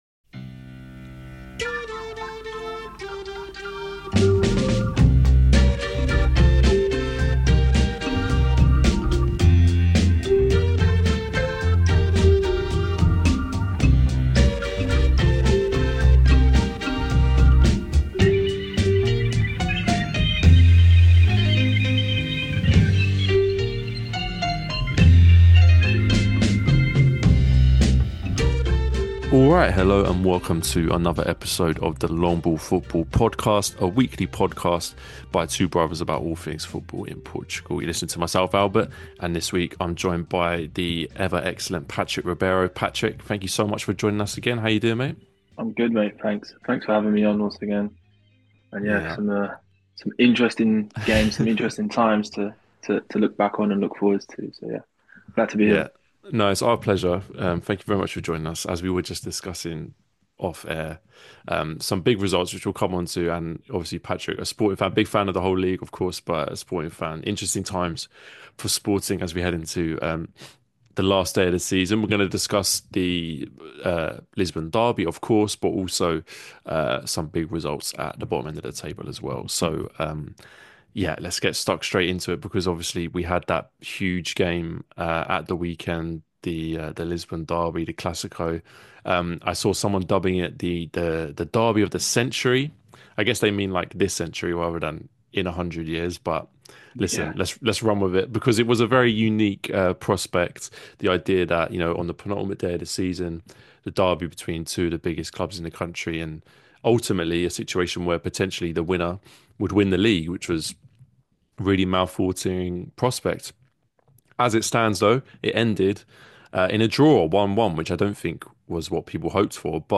A weekly podcast by two brothers about all things football in Portugal 🇵🇹⚽🇬🇧 Join us each week for Primeira Liga chat, and discussion about Portuguese clubs' exploits in Europe!